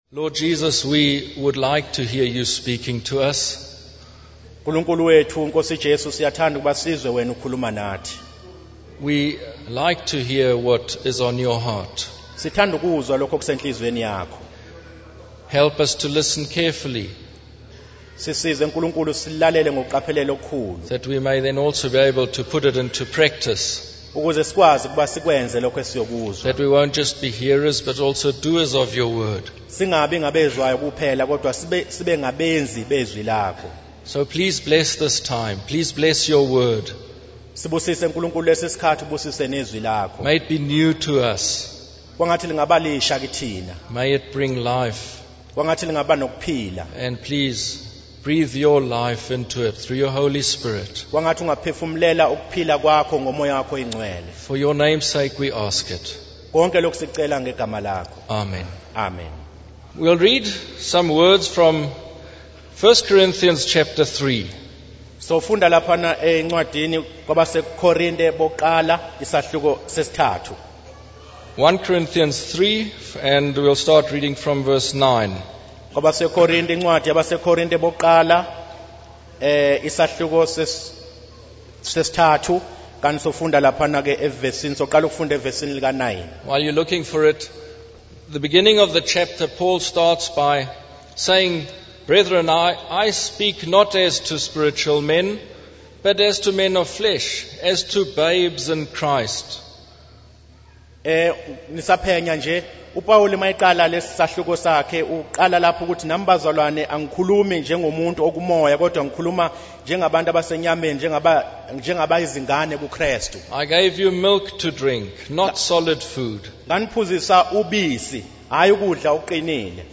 In this sermon, the speaker focuses on the importance of building our lives on the foundation of Christ Jesus.